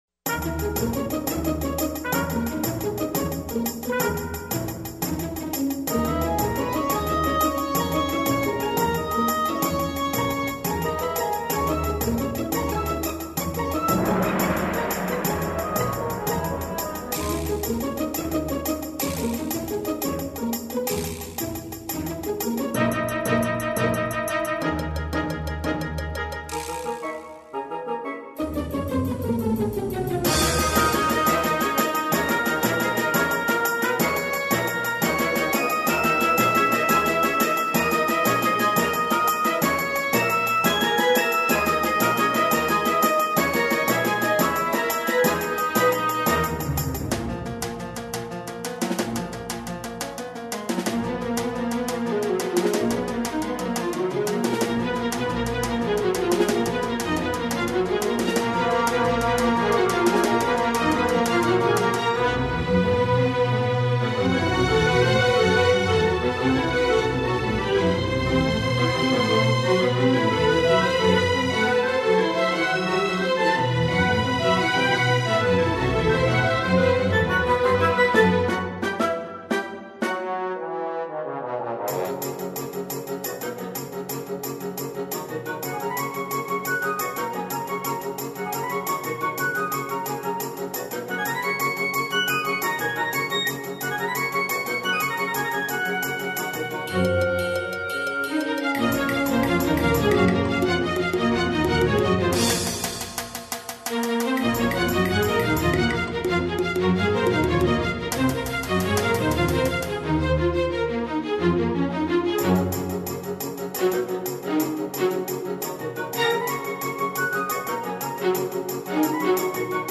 accessible and sonorous compositions